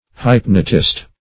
hypnotist - definition of hypnotist - synonyms, pronunciation, spelling from Free Dictionary
Hypnotist \Hyp"no*tist\, n.